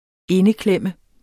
Udtale [ -ˌklεmˀə ]